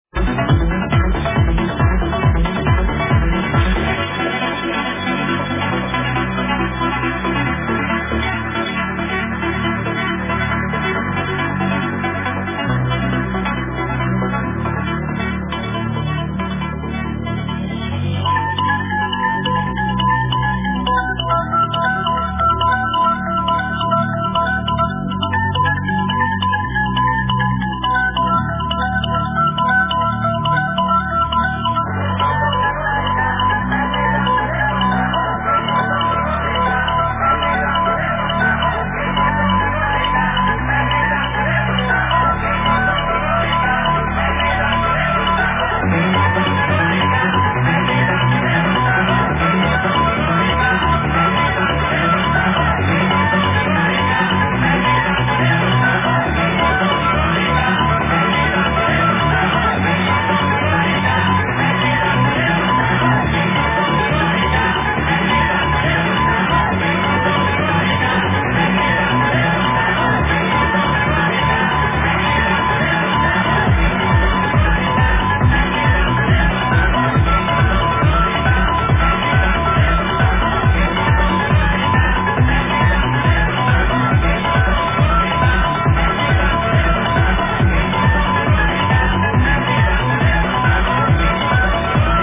need id...trance track...sample included :)